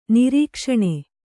♪ nirīkṣaṇe